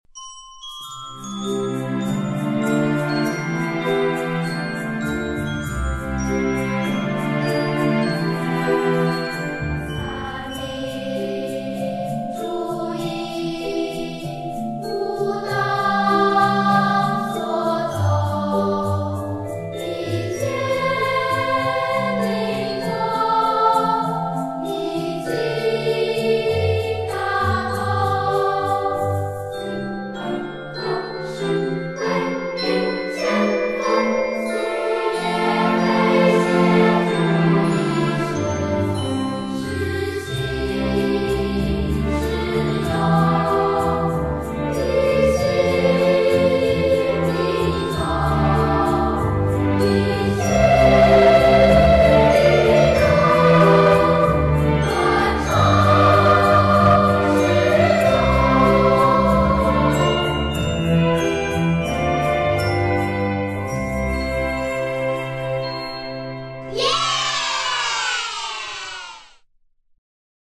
兒童篇(合唱版)